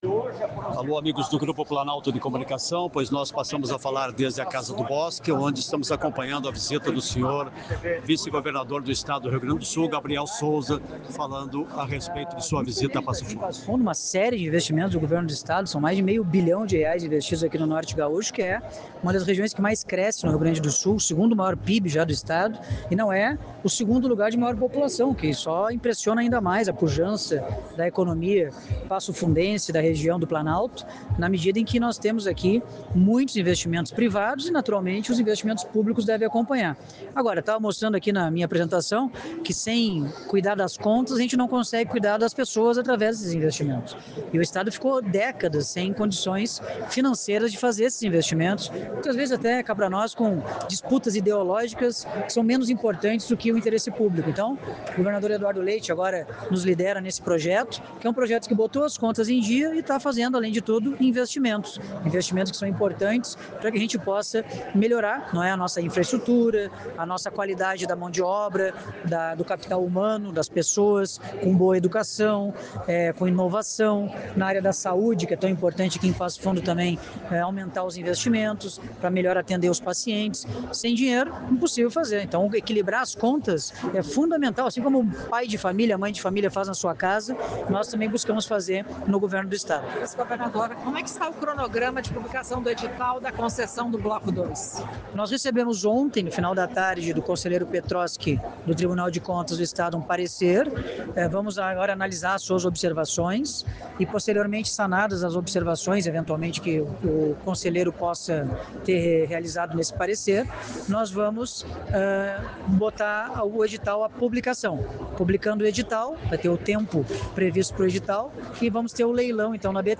A atividade foi realizada na Casa do Bosque, com reunião-almoço.
COLETIVA-GABRIEL-SOUZA-02-10.mp3